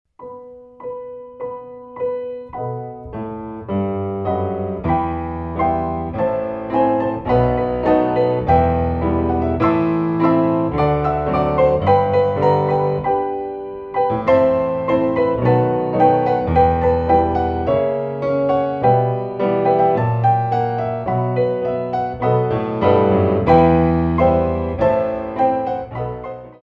Pirouettes Arrétées